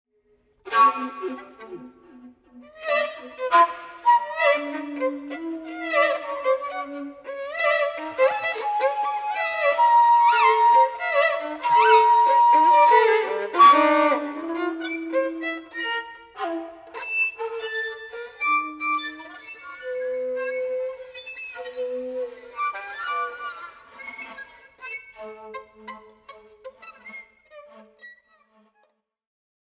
at bernard haitink hall of the conservatory of amsterdam
clarinet and shakuhachi
cello